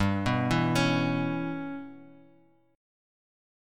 G6b5 Chord